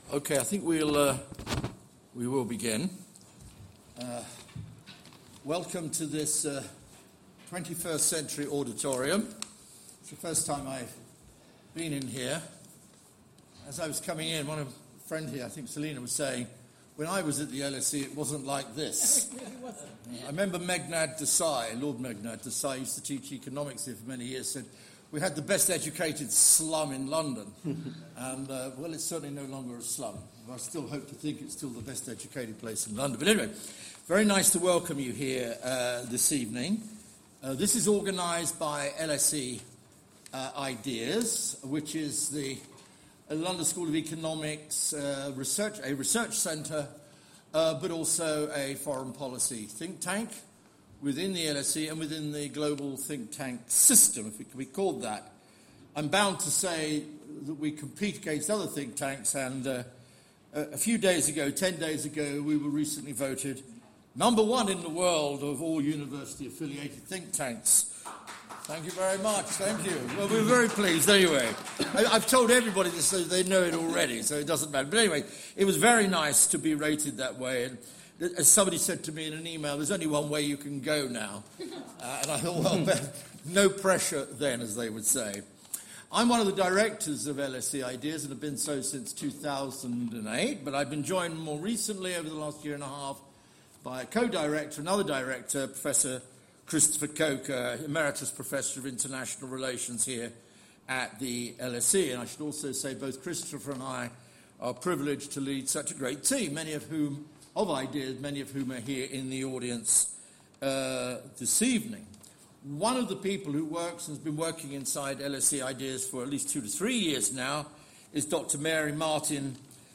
In this panel, academics, business representatives, NGOs and policy-makers discuss the benefits and challenges of working together, and the potential of creative partnerships to transform the world we live in.